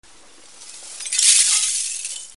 Vetri che si rompono
Rumore di vetro sottile che si frantuma.
Effetto sonoro - Vetri che si rompono